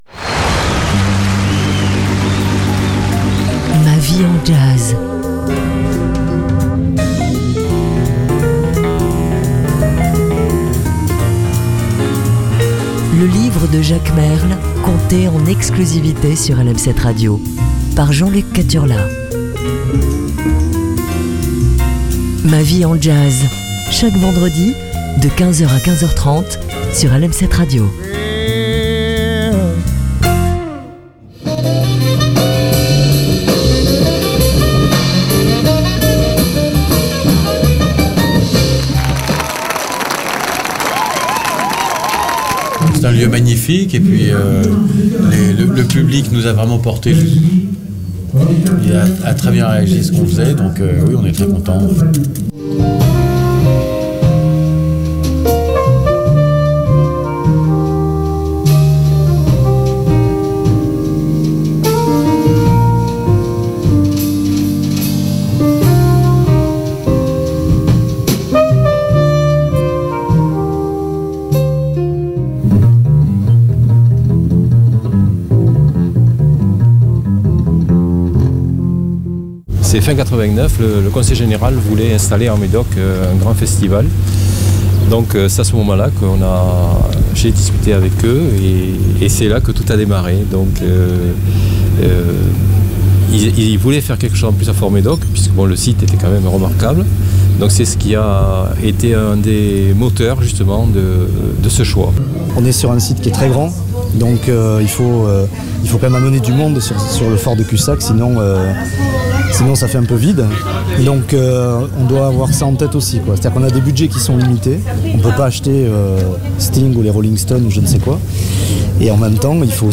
jazz & littérature